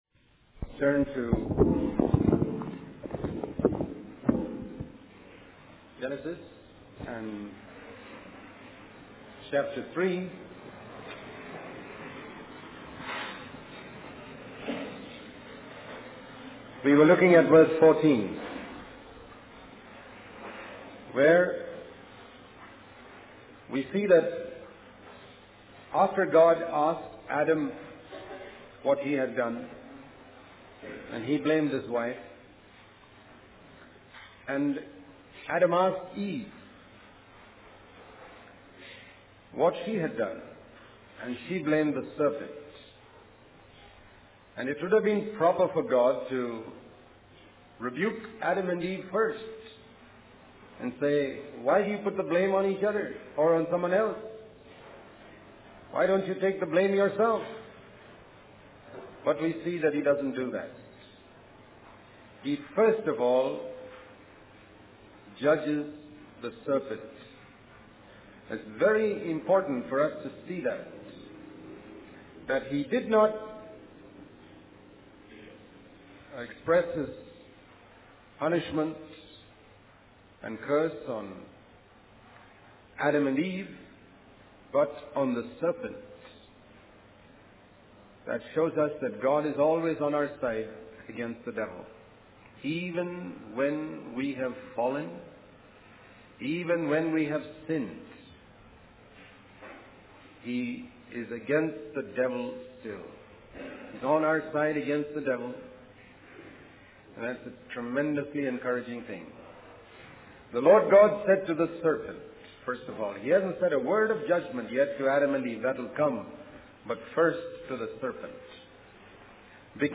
In this sermon, the speaker emphasizes the importance of hard work and discipline in the life of a believer.